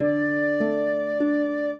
flute-harp
minuet15-5.wav